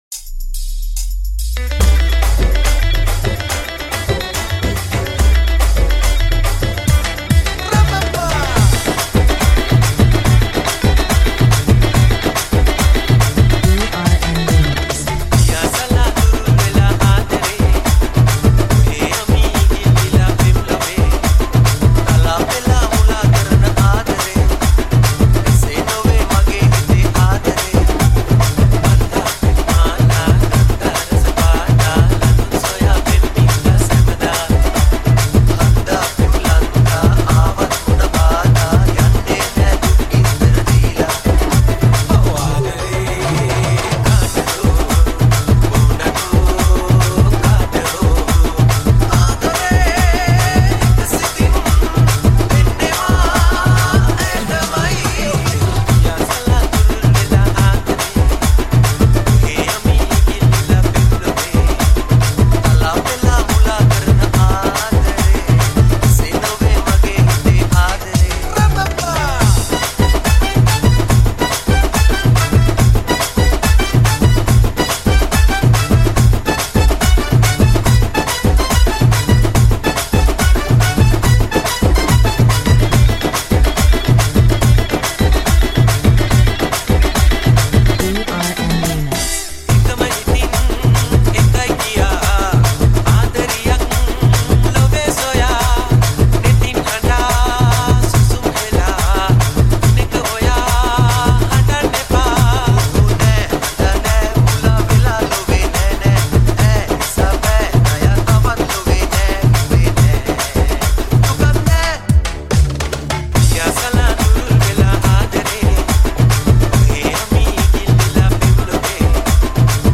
Baila Dance Mix